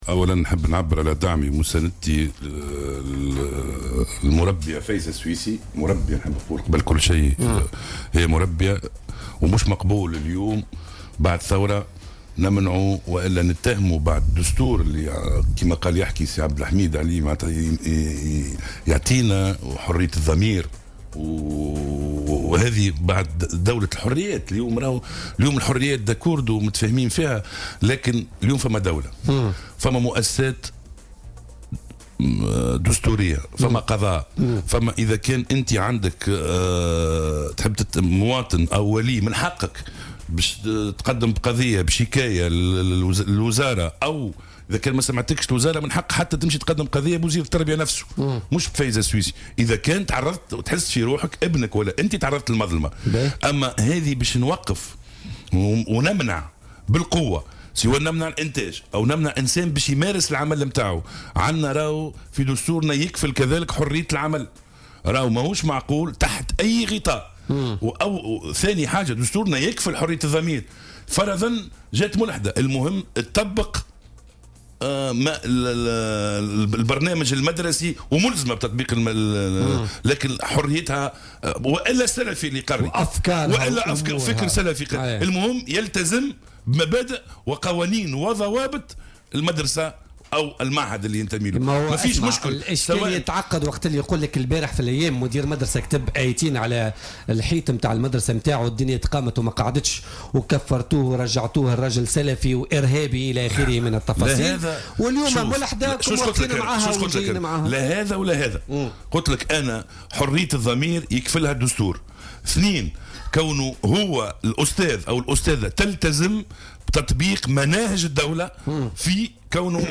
ضيف "بوليتيكا"